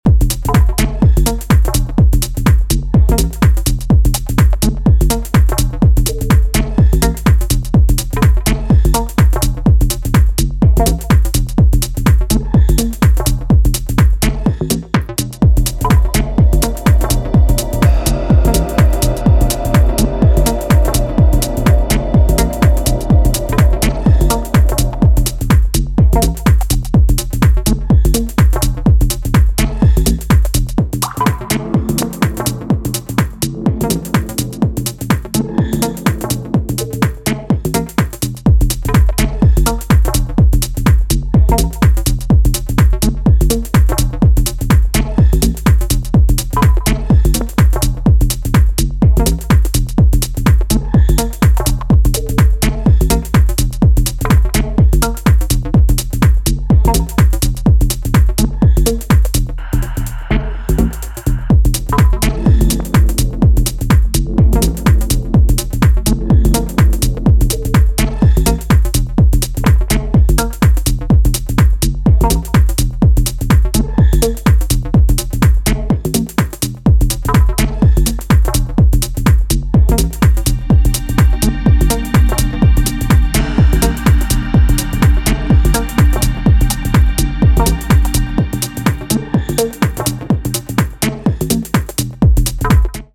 今回は全体的にダークでトリッピーなムードが際立っており、深い時間に良質な溜めを産んでくれるでしょう。